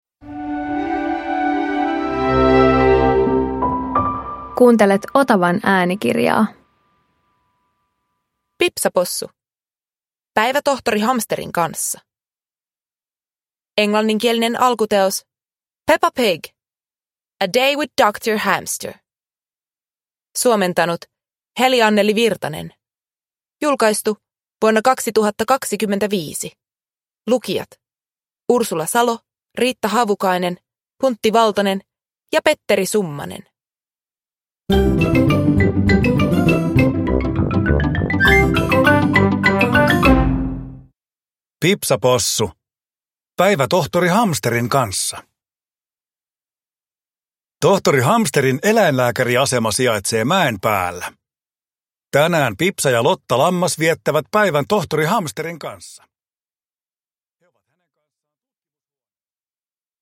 Pipsa Possu - Päivä tohtori Hamsterin kanssa (ljudbok) av Various